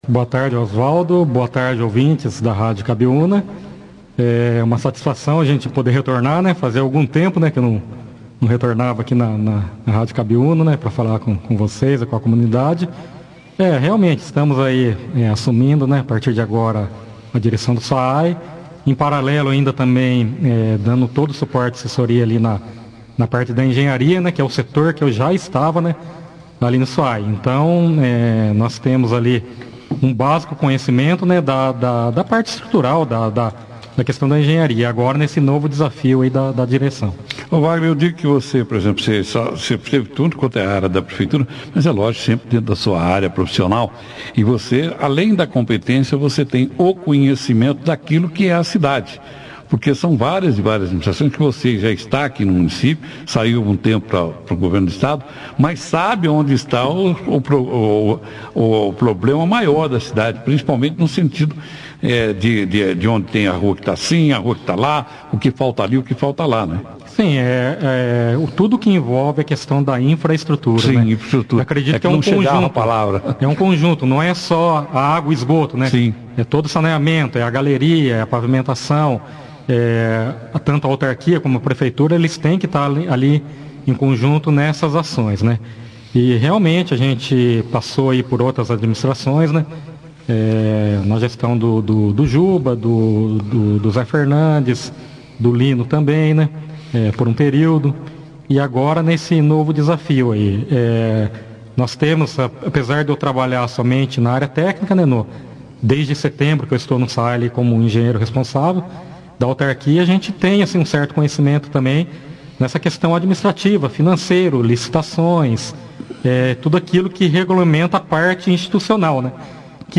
Após o ter seu nome aprovado pela Câmara de Vereadores na sessão da última segunda-feira, 02/05, o novo diretor do SAAE (Serviço Autônomo de Água e Esgoto de Bandeirantes), Engenheiro Civil Engenheiro Civil Wagner Toma, (foto)(foto), participou nesta quinta-feira, 05/05, da 2ª edição do jornal Operação Cidade, onde agradeceu a escolha do seu nome por parte da administração, a aprovação pelo plenário da câmara e daquilo que pretende realizar à frente da autarquia.